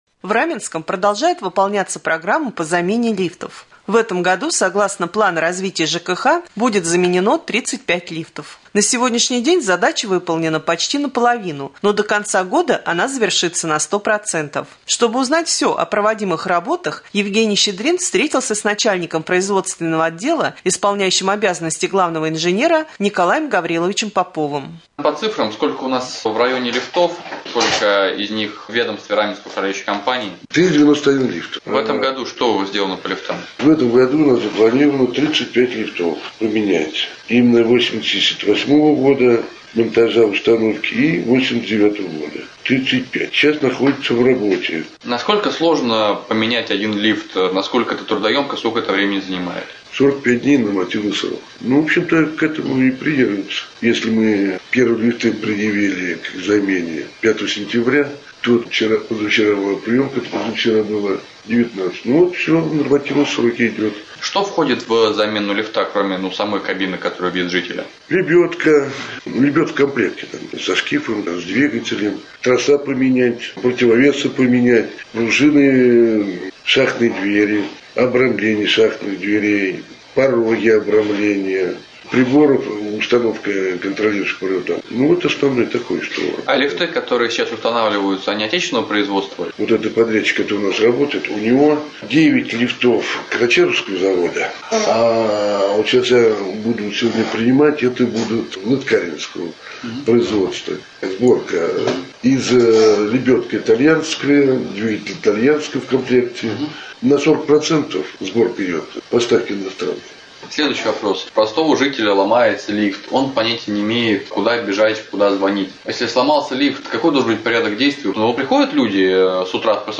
3. Новости